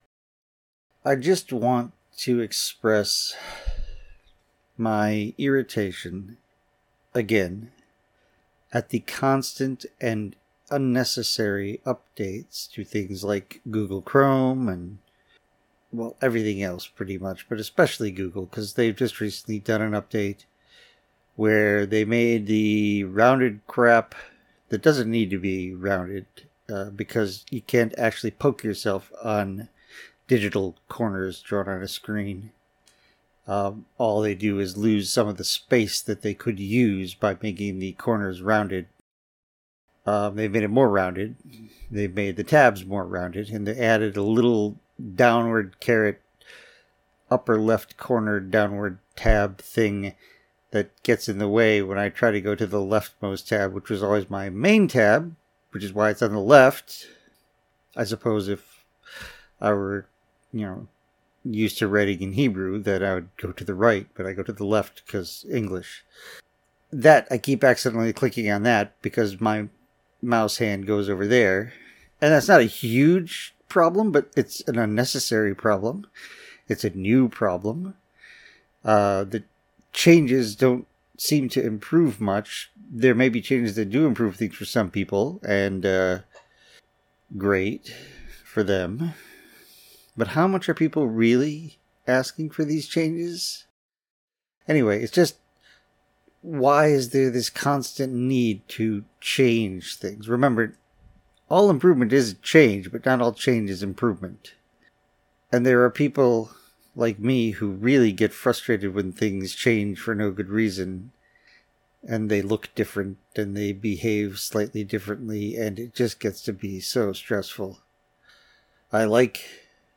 I did a little recording on Friday of a few minutes of a rant about the useless updates that the various software sites keep undergoing.